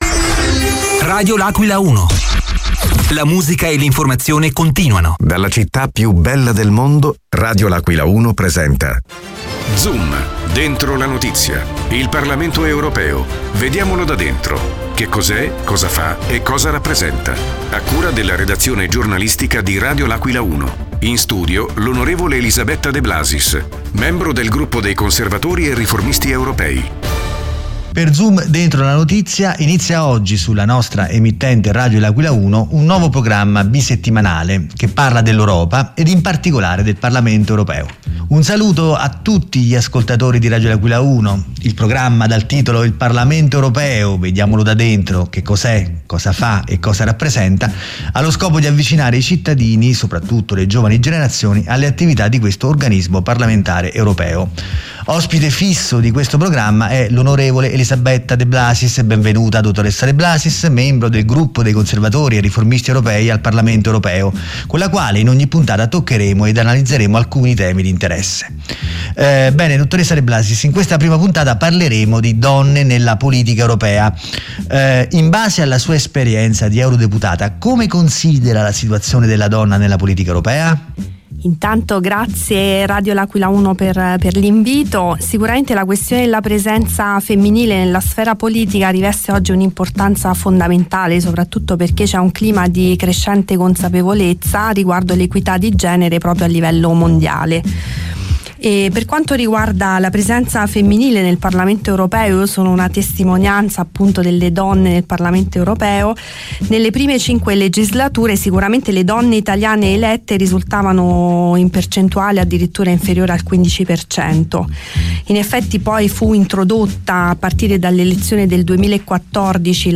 L’AQUILA – Inizia oggi su Radio L’Aquila 1 una serie di trasmissioni bisettimanali di approfondimento sulle attività del Parlamento Europeo con ospite fisso in studio l’onorevole Elisabetta De Blasis, membro del Gruppo dei Conservatori e Riformisti Europei.